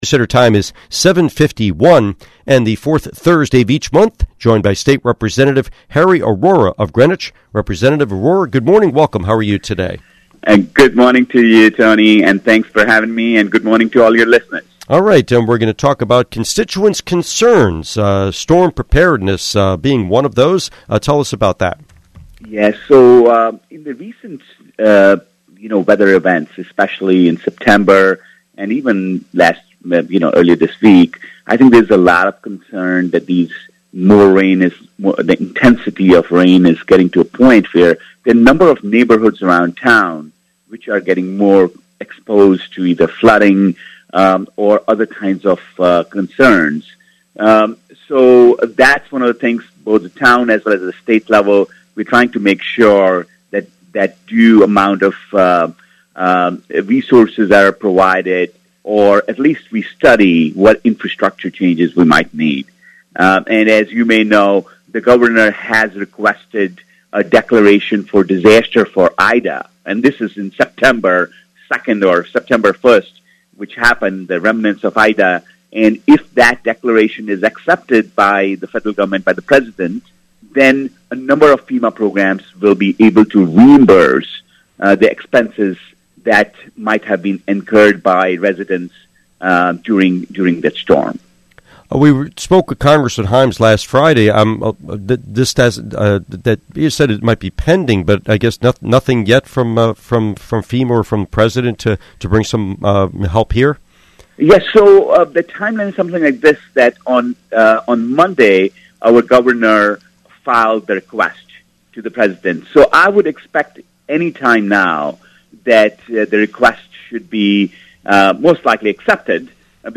Interview with State Representative Arora